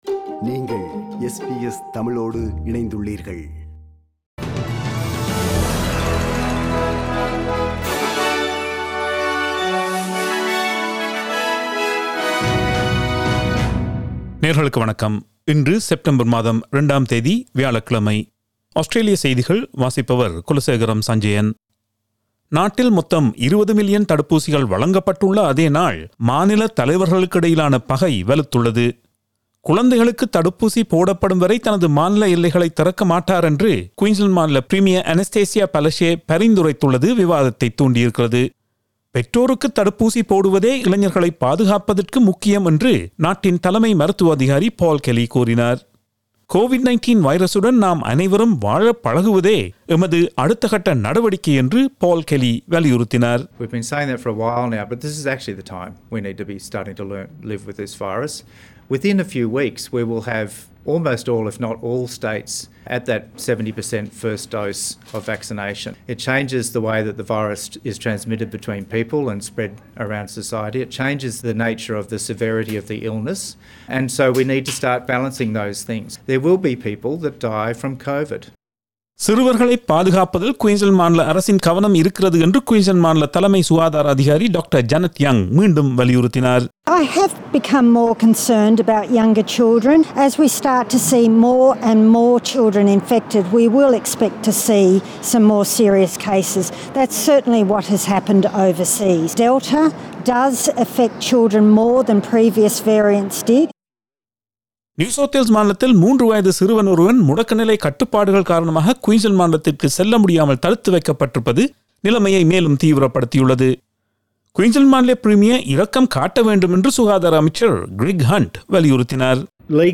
Australian news bulletin for Thursday 02 September 2021.